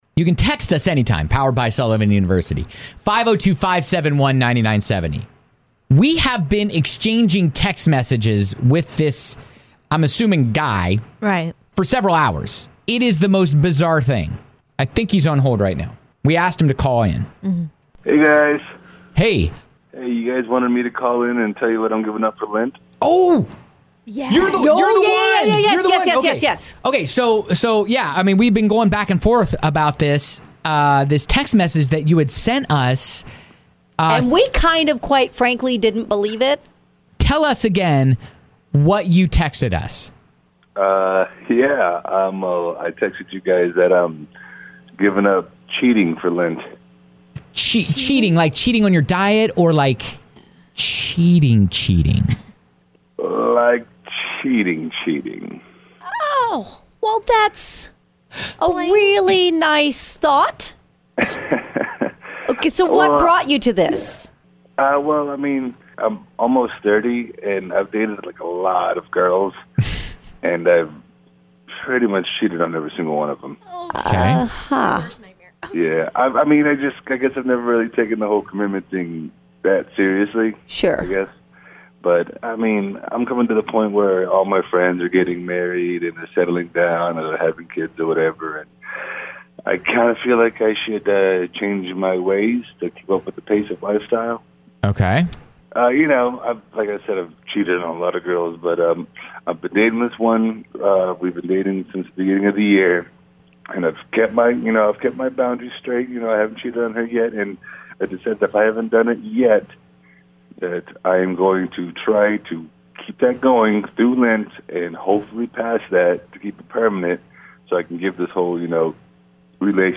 After exchanging several messages with this guy...we convinced him to call us and explain what he was "sacrificing" for Lent.